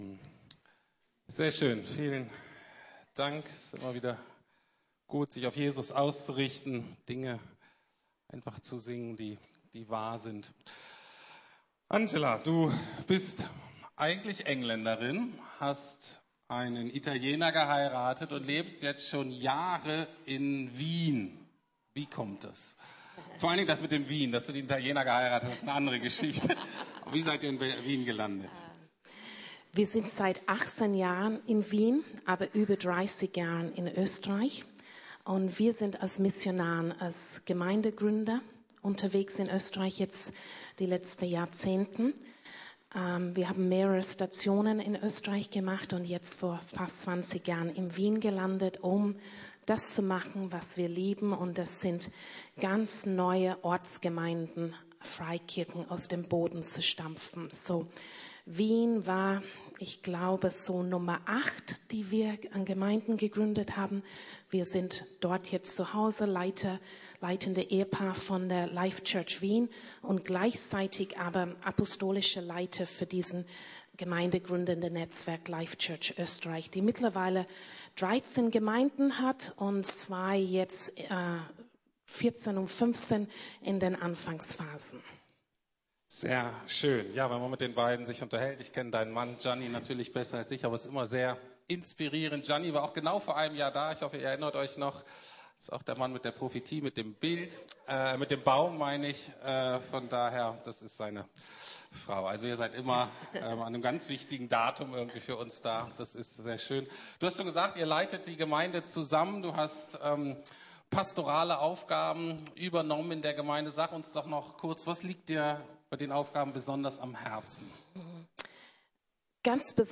Gott durchs Vergrößerungsglas ~ Predigten der LUKAS GEMEINDE Podcast